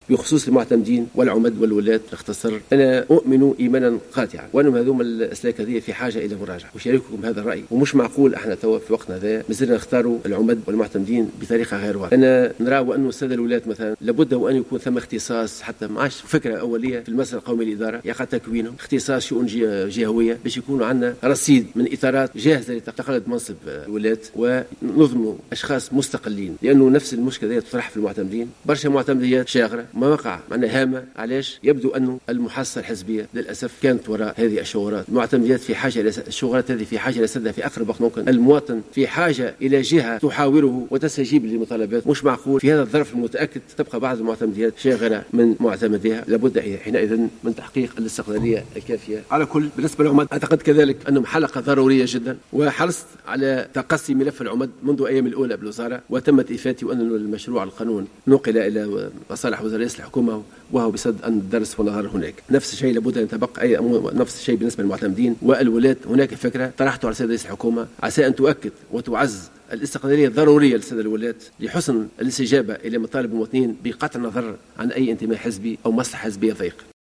شدد وزير الداخلية توفيق شرف الدين في كلمته اليوم خلال الجلسة العامة المخصصة لمناقشة مشروع ميزانية الوزارة على توفير الإطار القانوني الذي ينظم سلك المعتمدين والعمد والولاة لتحييده عن التجاذبات و المحاصصات الحزبية.